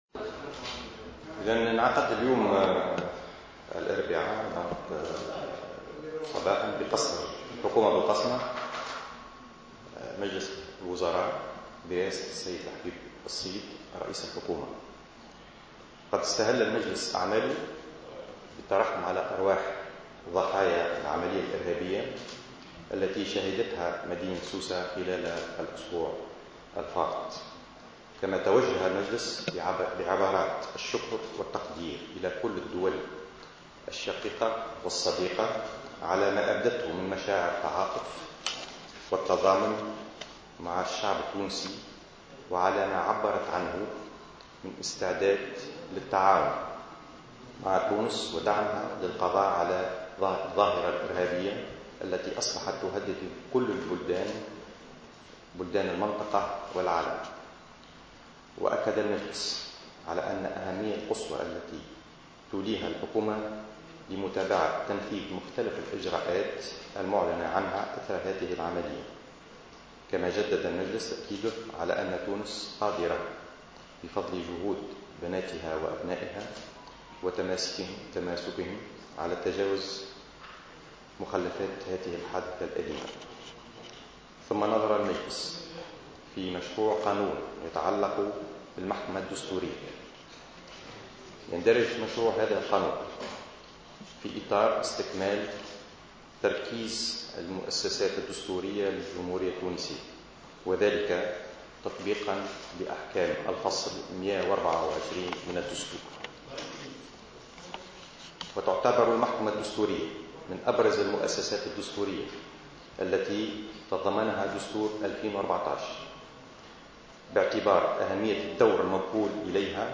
على هامش ندوة صحفية